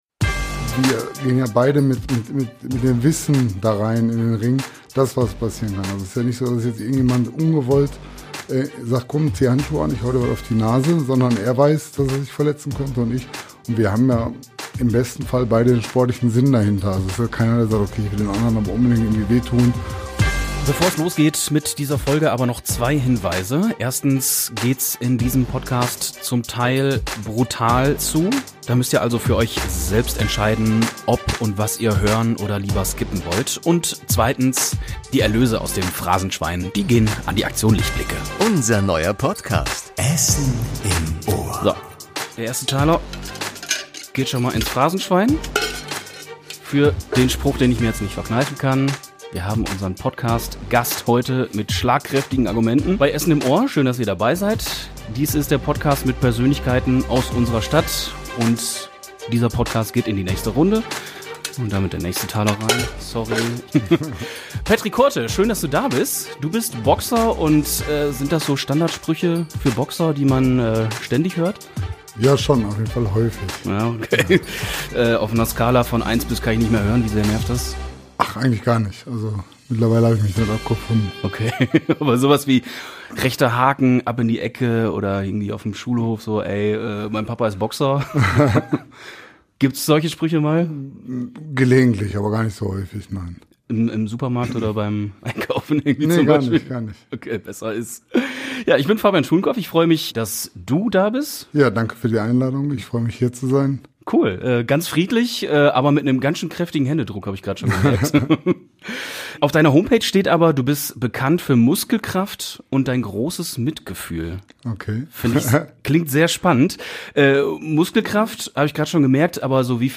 Der Talk mit Persönlichkeiten aus der Stadt Podcast